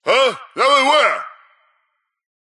Henchman_Suspicious_010